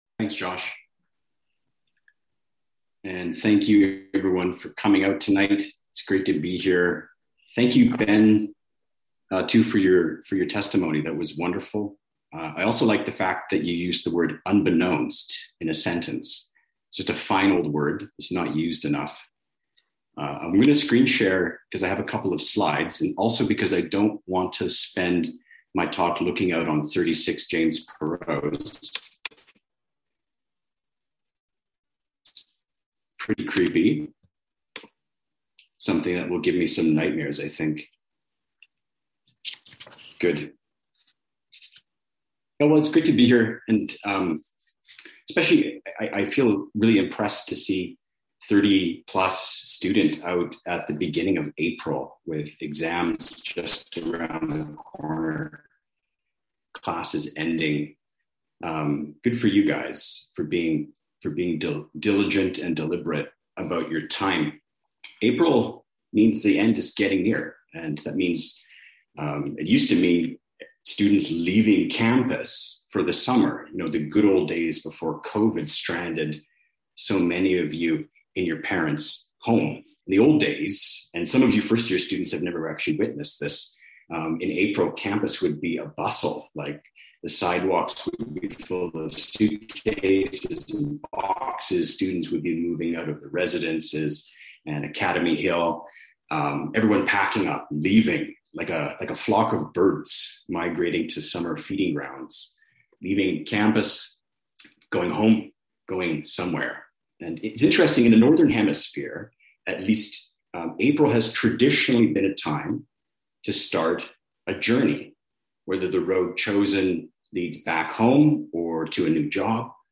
Student Life as Pilgrimage In this stand alone sermon